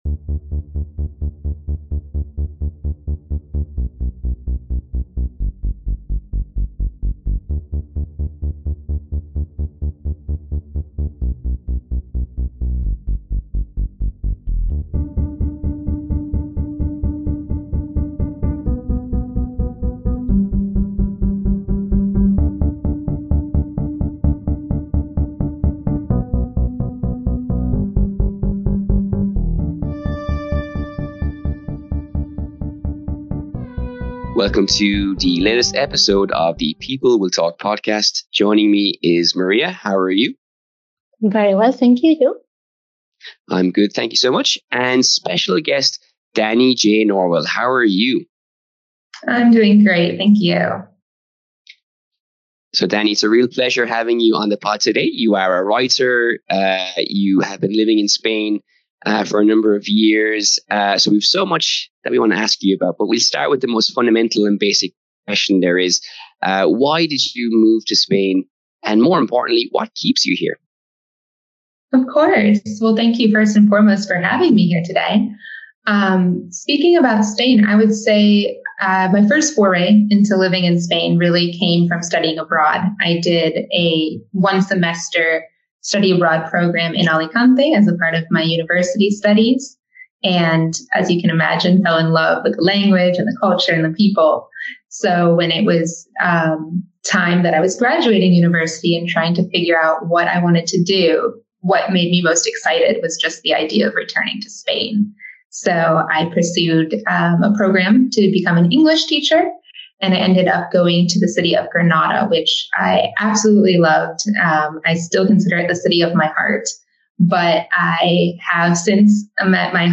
Blogger & Writer: Interviewing